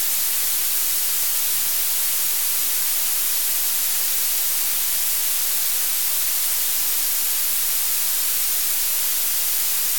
这两个可以放在一起说，因为蓝噪声和紫噪声跟前面介绍的粉噪声和红噪声很像，只是它们的能量在频率上的分布是反过来的，都是比较“高频”的噪声。
蓝噪声
蓝噪声-BuleNoise.mp3